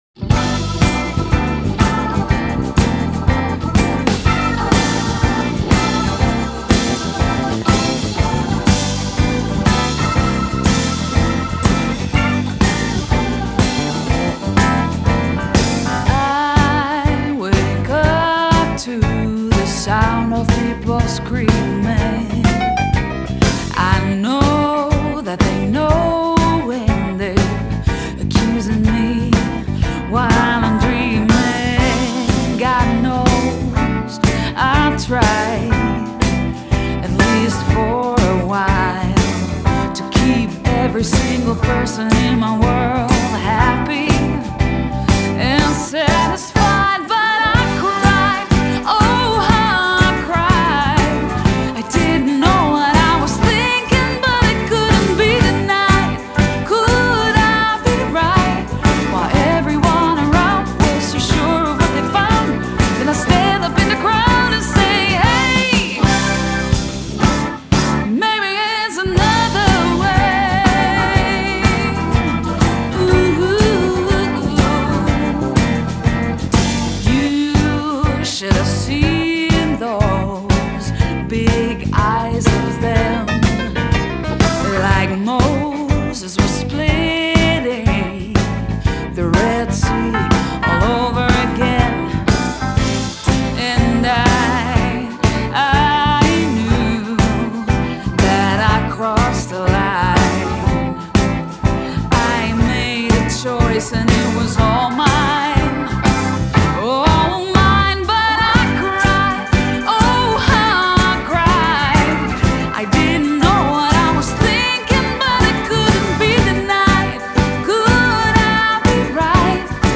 Genre: Pop, Folk Pop, Jazzy Pop